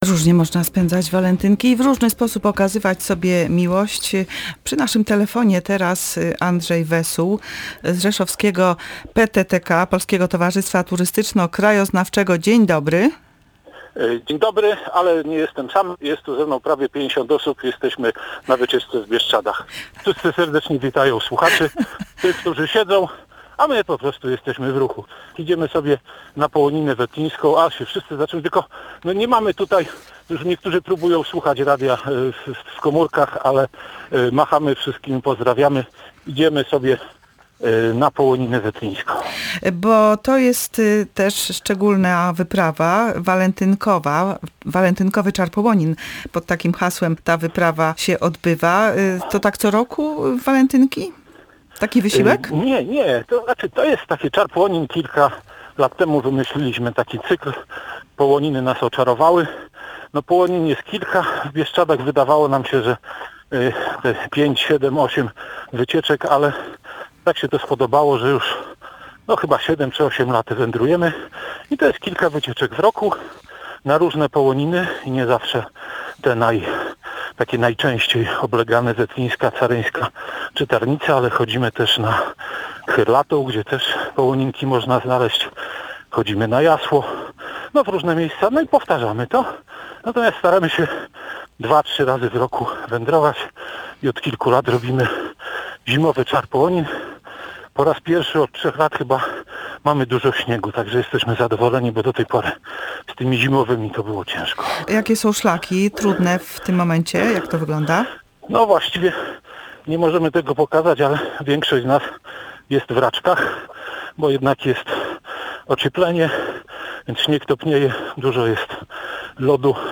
Rozmowę w audycji „Wolna Sobota”